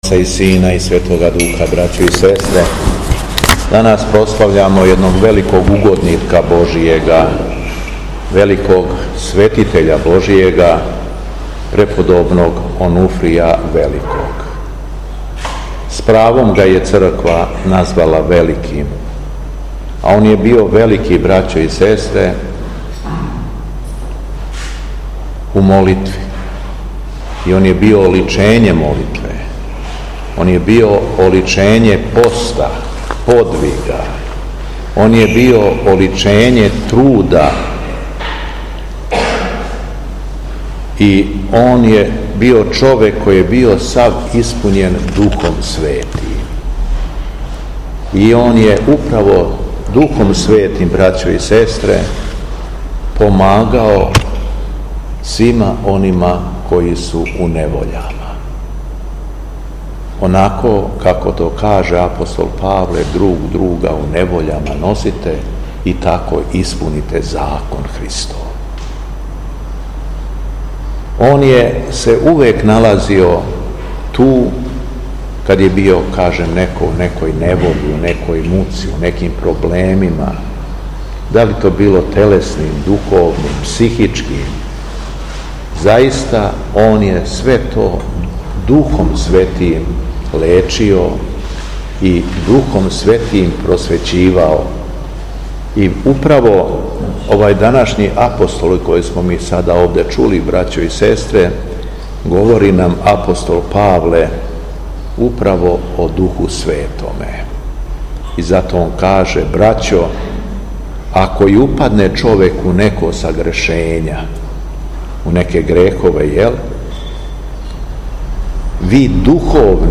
Беседа Његовог Високопреосвештенства Митрополита шумадијског г. Јована
После прочитаног јеванђелског зачала Високопреосвећени се обратио верном народу надахнутом беседом: